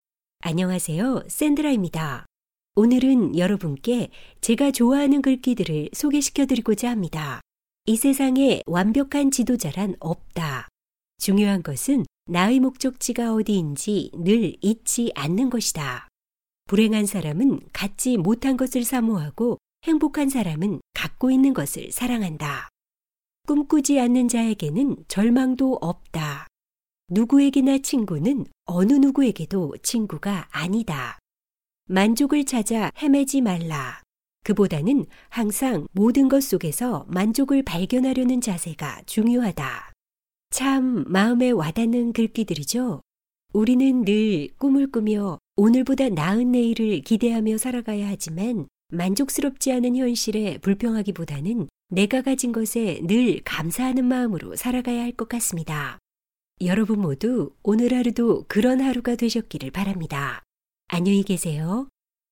韩语样音试听下载
Kr-female-DK007-demo.mp3